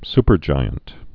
(spər-jīənt)